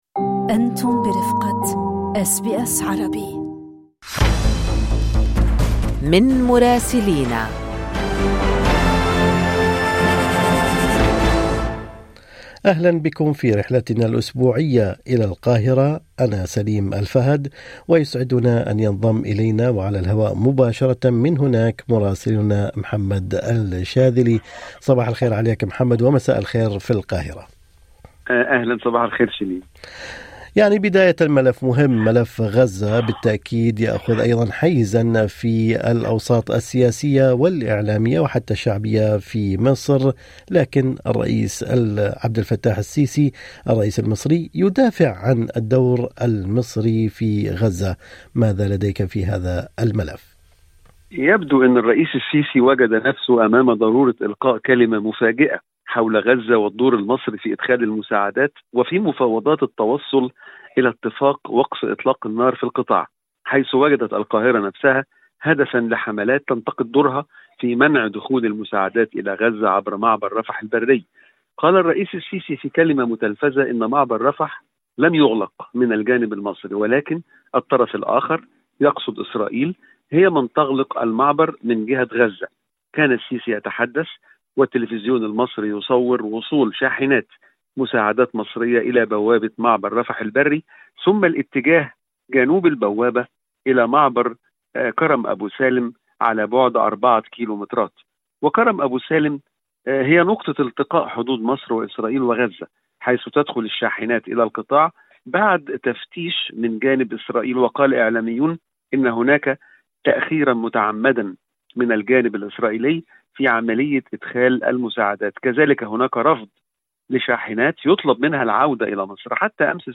تقرير المراسل